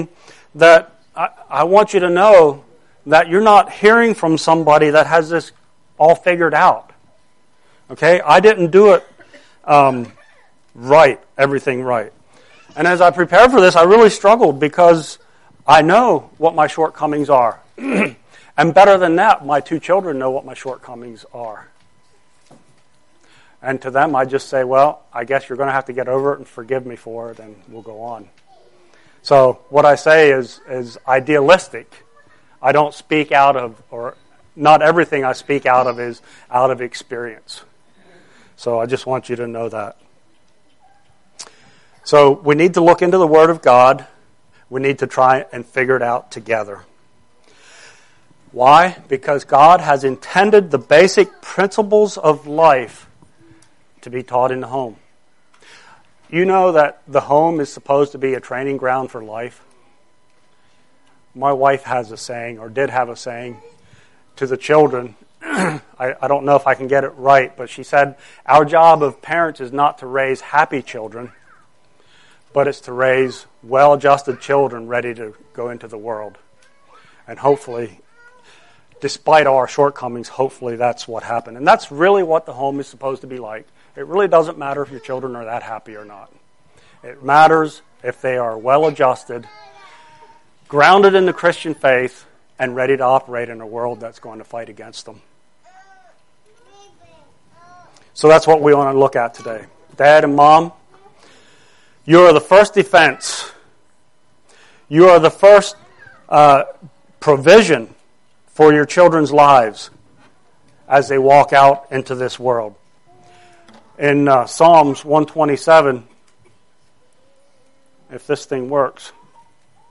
Due to technical issues we did not get the first couple of minutes recorded.
Download Sermons Previous Post Knowing God